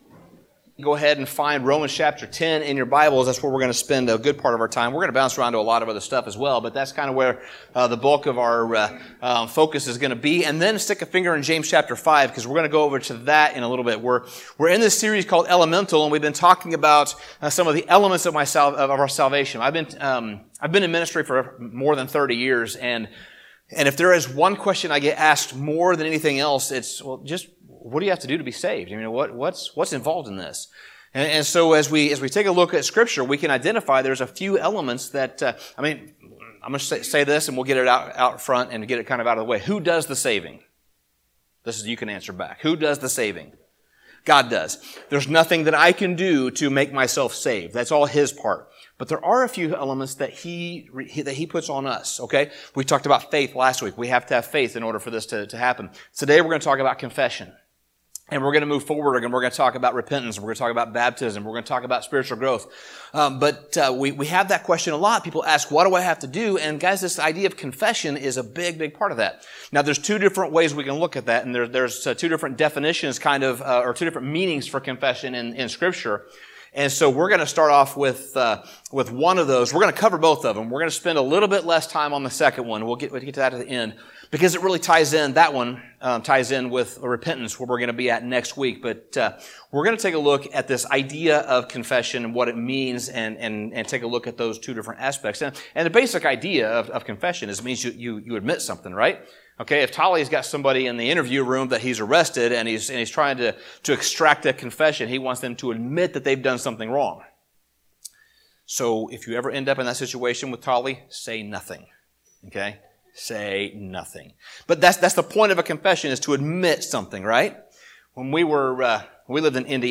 Sermon Summary Confession is the second element that we need to understand.